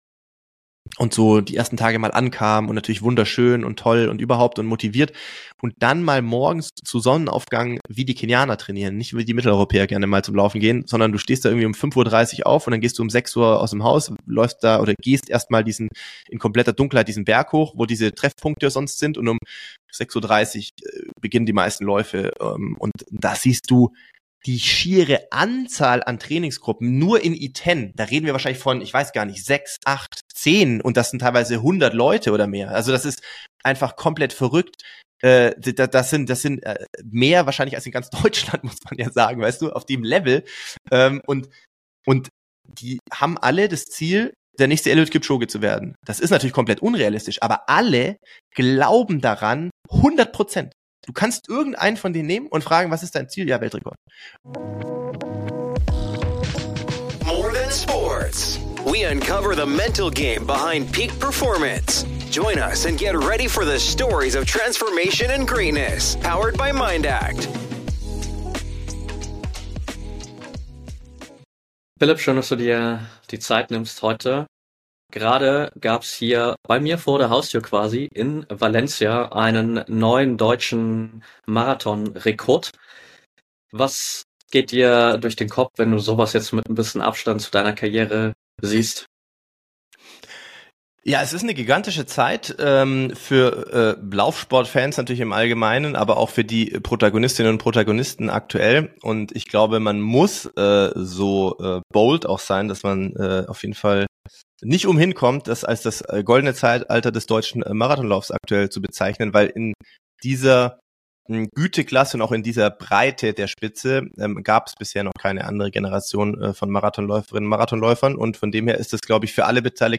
1 Wort und Musik mit "Cantus Felix", Basilika Güssing - Einstimmung auf Weihnachten 2024 1:14:03 Play Pause 5h ago 1:14:03 Play Pause Später Spielen Später Spielen Listen Gefällt mir Geliked 1:14:03 Wir senden in einer Aufzeichnung Mitschnitte vom Konzert am 23. Dezember 2024 mit Cantus Felix in der Basilika Güssing, im Südburgenland, Österreich.
Klavier
Das Vocalensemble Cantus Felix wurde im Herbst 1991 gegründet.